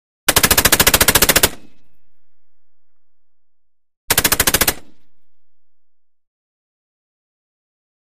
Tank Machine Gun: Bursts; Long Bursts Of Tank Machine Gun. Two Huge Bursts With Short Echo Roll Off. Close Up Perspective.